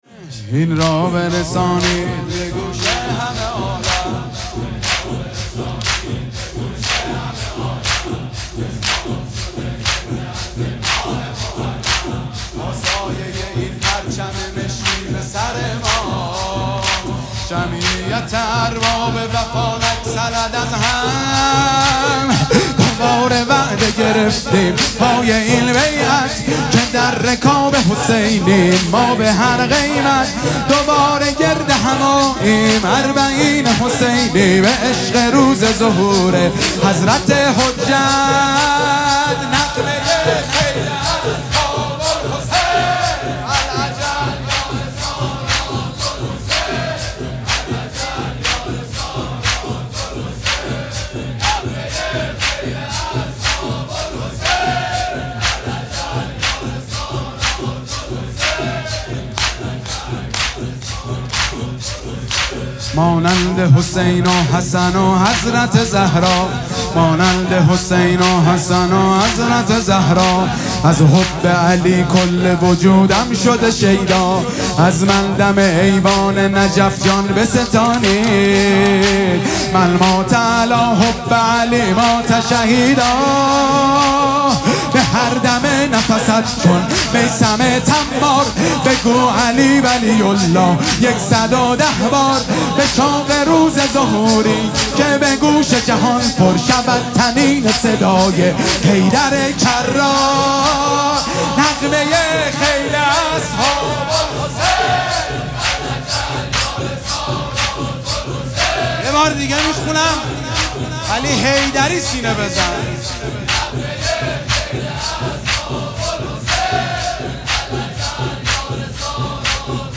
مداحی شب هفتم محرم
با حضور جمعیت کثیری از عزاداران حضرت سیدالشهدا علیه السلام برگزار گردید